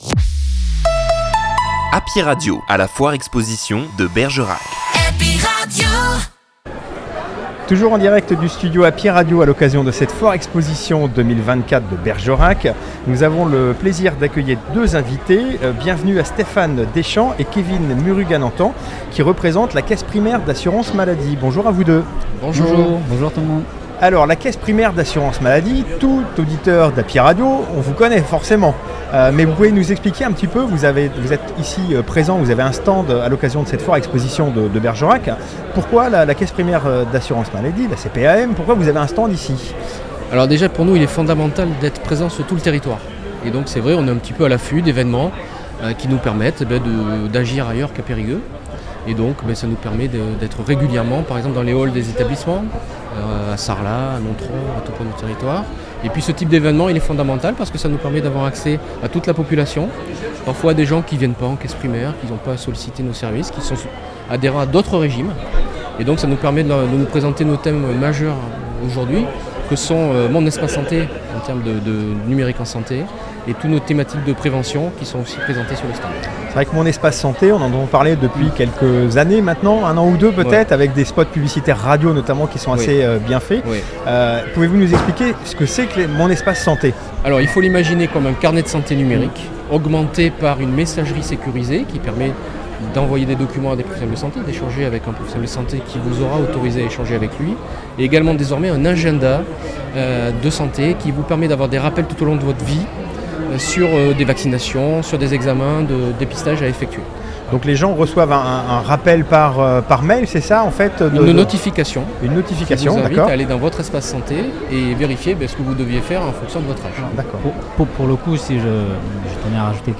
Foire Expo De Bergerac 2024